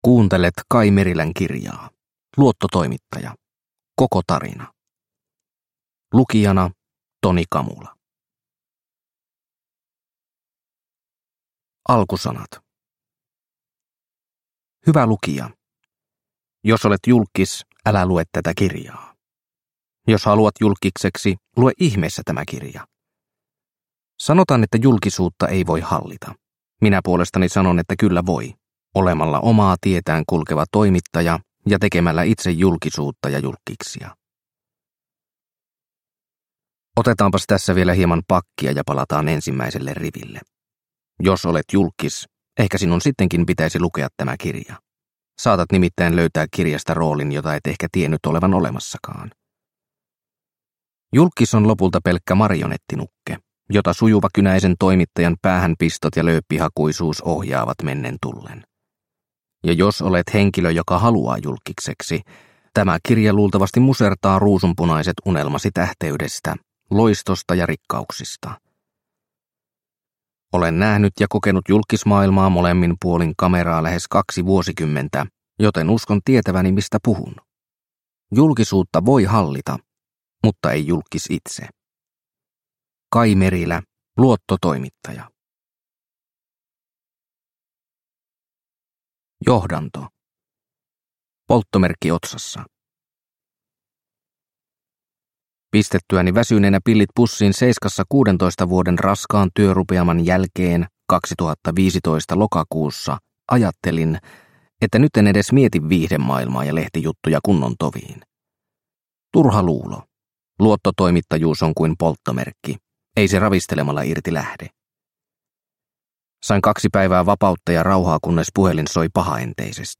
Luottotoimittaja – Ljudbok – Laddas ner